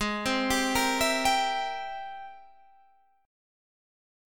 G#+M9 chord